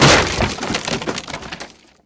CrateHit.wav